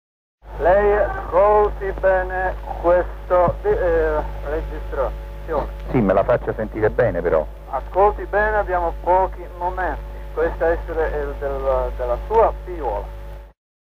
L'appello del Papa